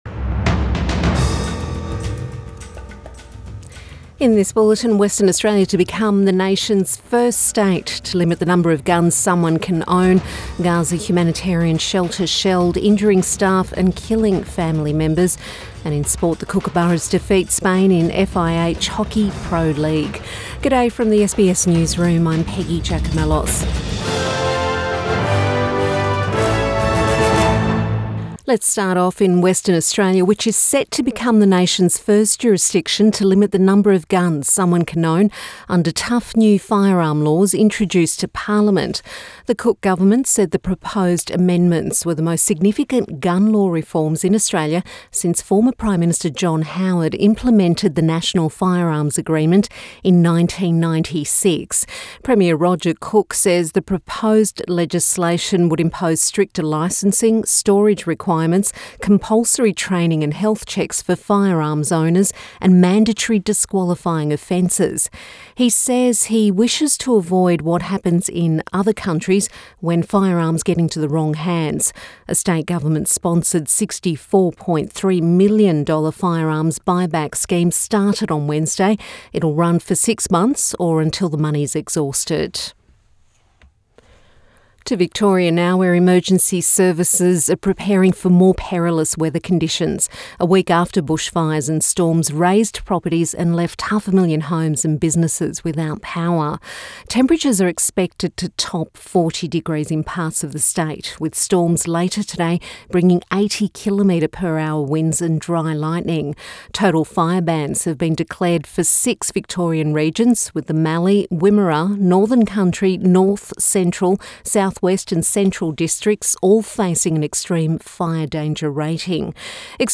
Morning News Bulletin 22 February 2024